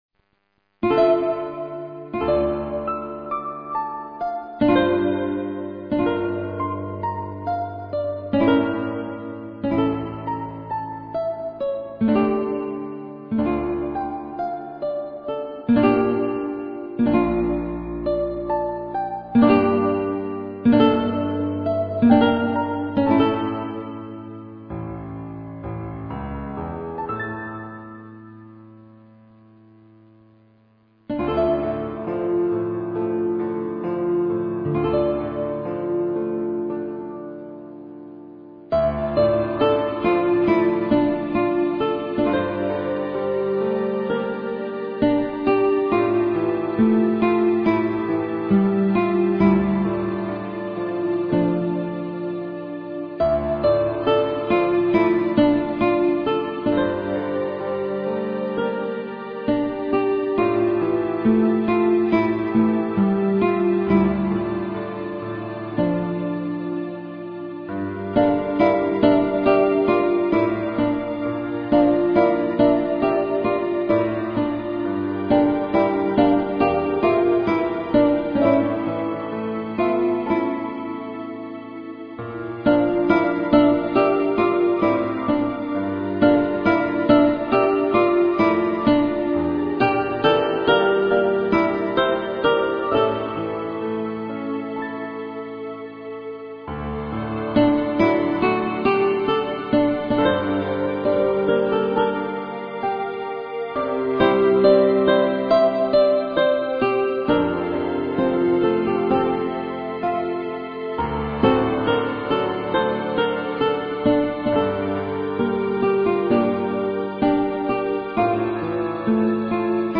アンサンブル曲